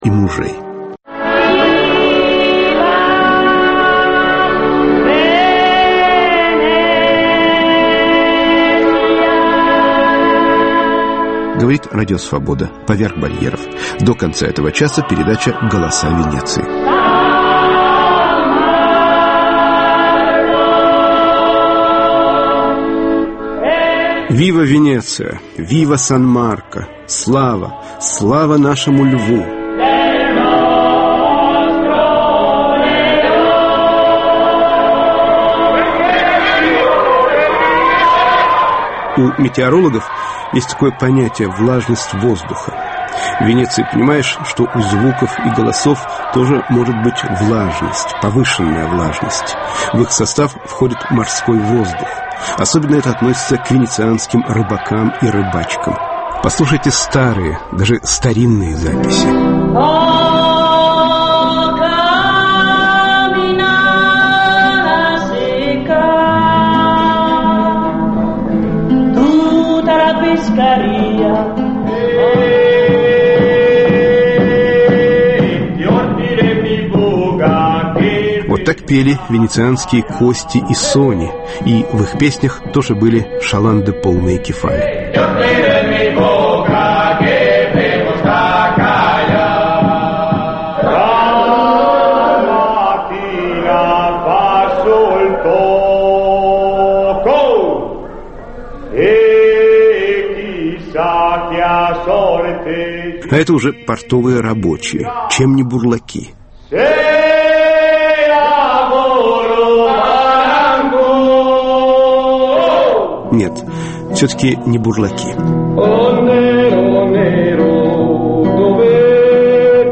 "Голоса Венеции": акустический портрет города